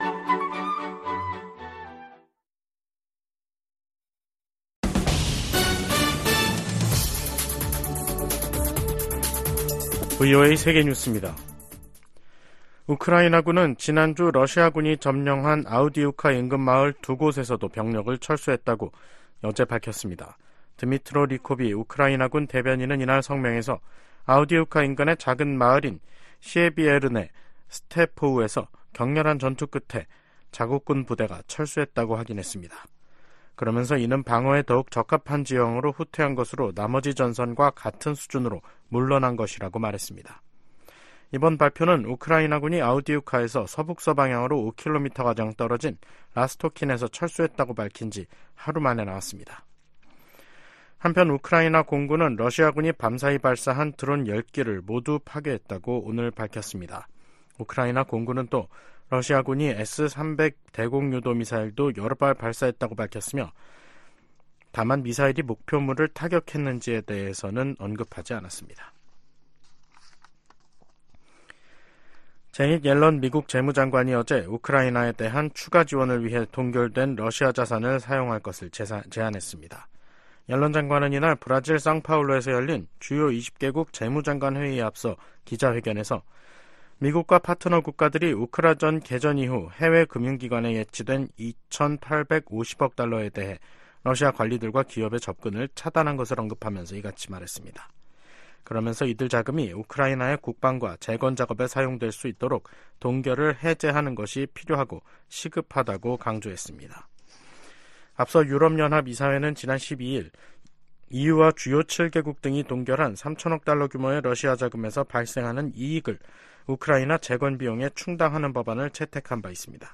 VOA 한국어 간판 뉴스 프로그램 '뉴스 투데이', 2024년 2월 28일 2부 방송입니다.